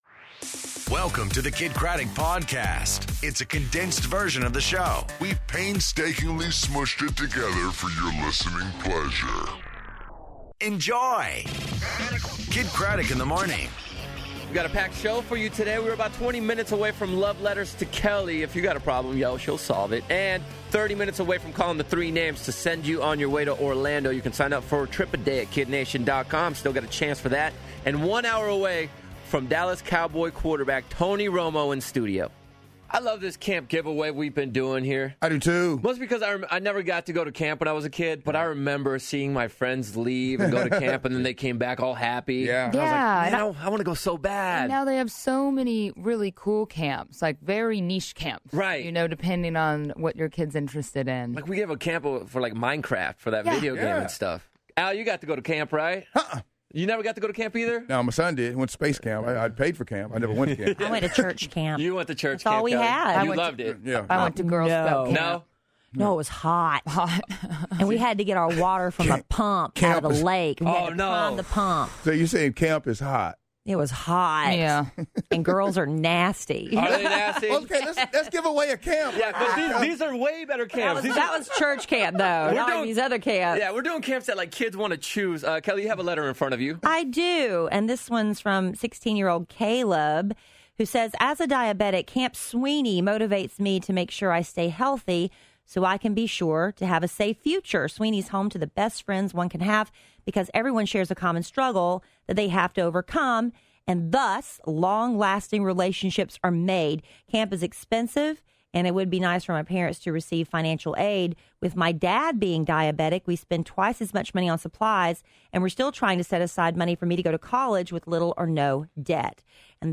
Tony Romo In Studio